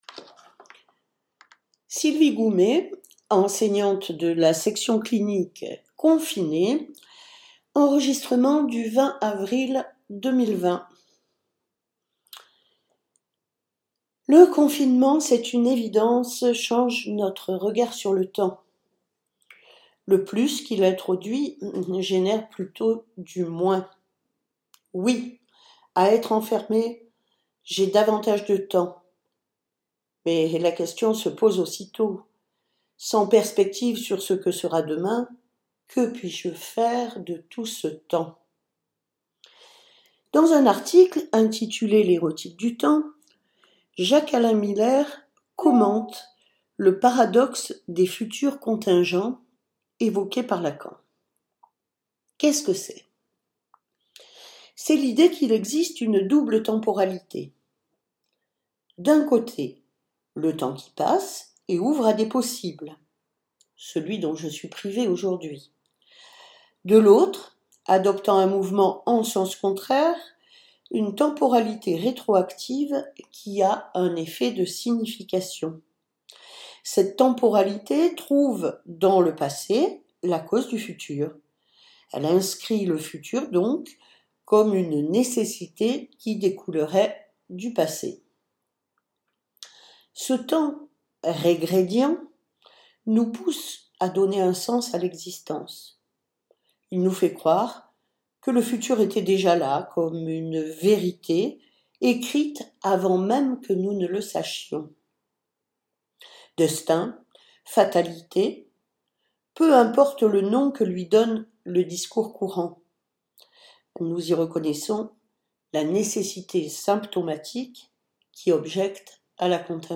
Elle nous propose un commentaire suivi de la lecture d’un extrait du livre Les Gommes , paru aux Éditions de Minuit en 1953, intitulé » Prologue » Les gommes, d’Alain Robbe-Grillet, est un excellent roman policier qui, pourtant, démonte les rouages et gomme les codes du genre. L’auteur joue de la temporalité tout en respectant la chronologie.